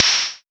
• Urban Open High-Hat Sound F Key 04.wav
Royality free open high-hat tuned to the F note. Loudest frequency: 4383Hz
urban-open-high-hat-sound-f-key-04-wTU.wav